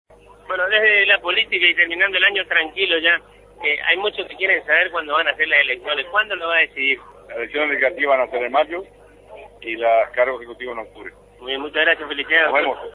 (Audio) En contacto con el equipo de exteriores de Agenda 970 a través de Radio Guarani el gobernador de la provincia de Corrientes Ricardo Colombi confirmó el desdoblamiento de las elecciones provinciales. Dijo que en mayo se elegirán diputados y senadores (legislativas) y en el mes de octubre se realizarán las elecciones a cargos ejecutivos, o sea quien lo va a suceder en el sillón de Ferré.